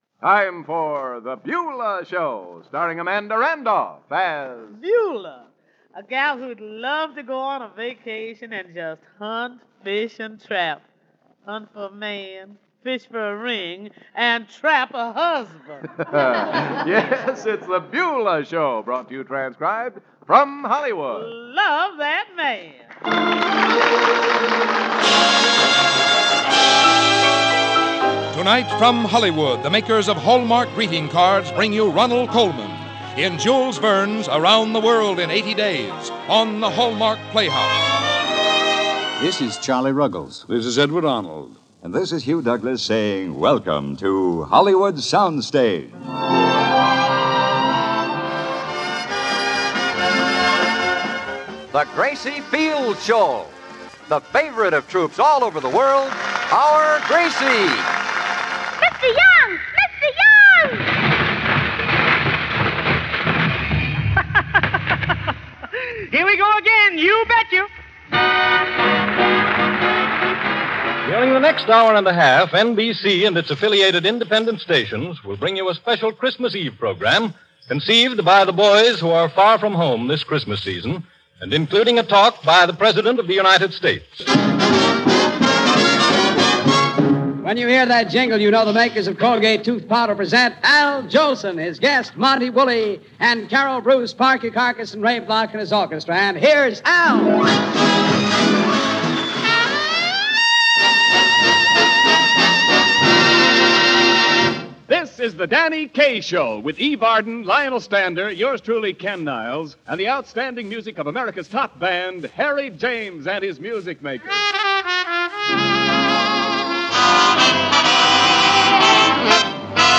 This exciting series is derived from our massive collection of thirty thousand radio shows from 16" transcription discs and fifty thousand shows from low generation reels.
You'll find rare and obscure as well as mainstream radio shows from the 1930s, 1940s, and 1950s in the Radio Archives Treasures sets. These shows have all been restored with state-of-the-art CEDAR technology - the audio processing system used by major recording companies to restore older recordings.